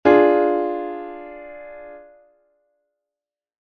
Que tipo de acorde estás a escoitar?
diminuido2.mp3